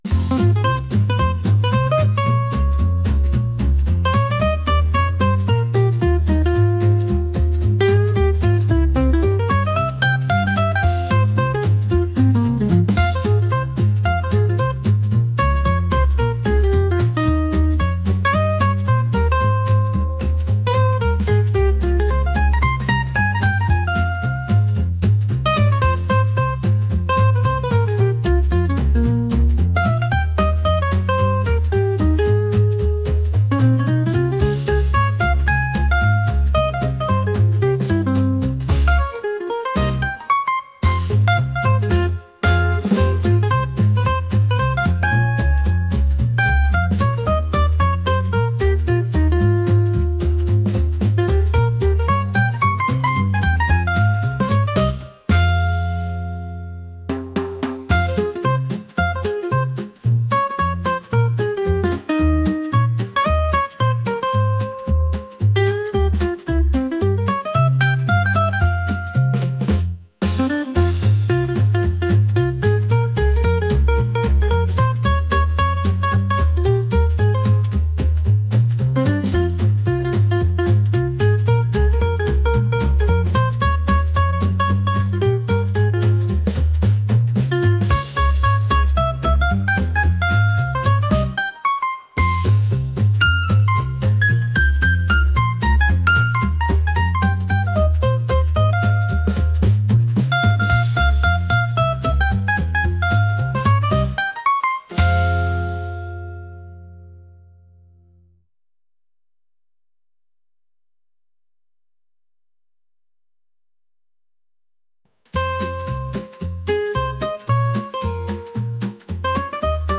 energetic | jazz